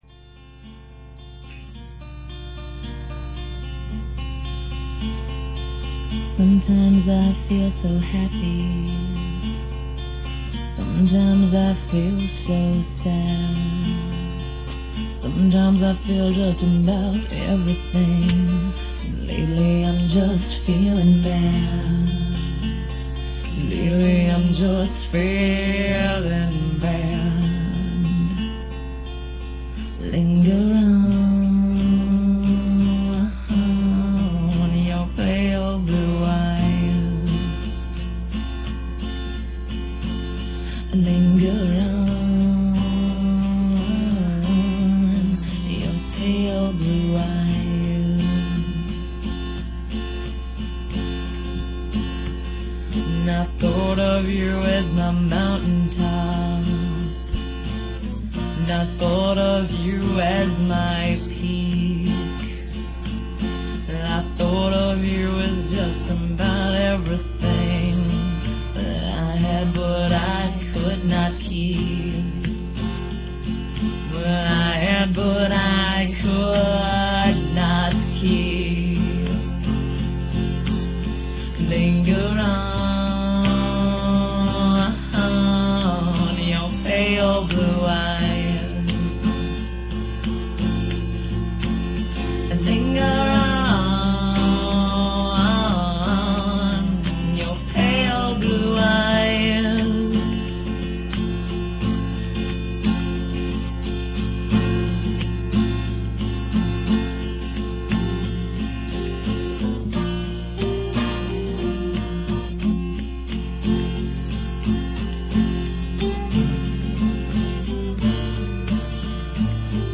and was recorded in Chicago.